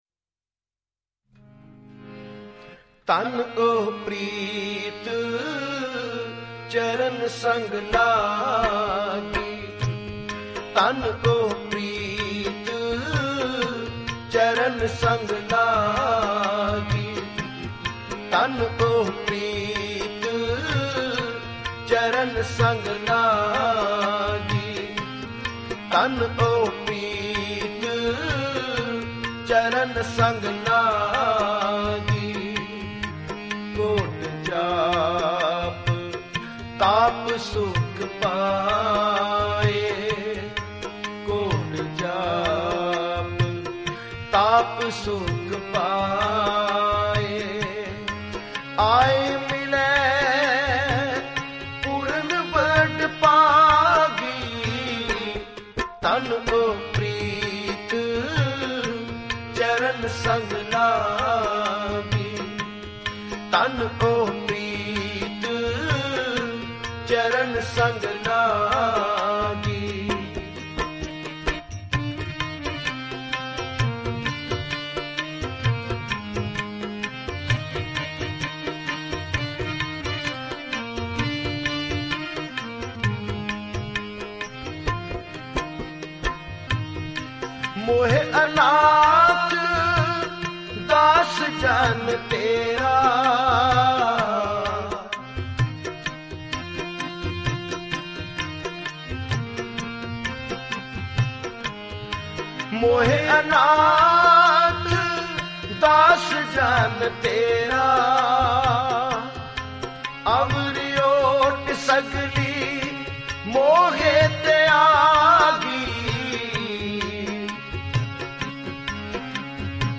Category: Shabad Gurbani